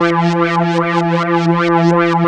3100 AP  E 4.wav